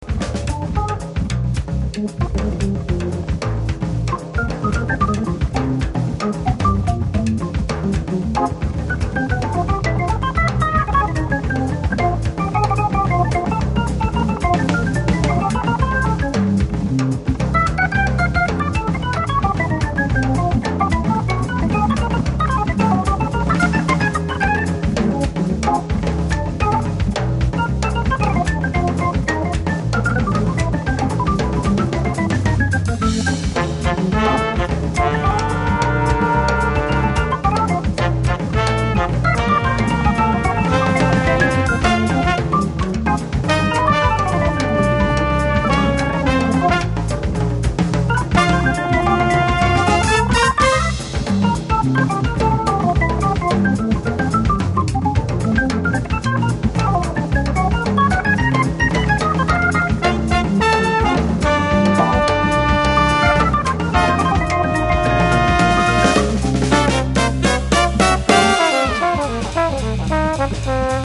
on peut avoir un peu de solo d'orgue patron?